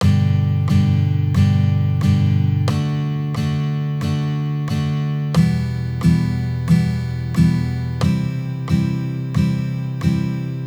Now, the I-V-vi-IV chord progression in G Major.
I-V-vi-IV Chord Progression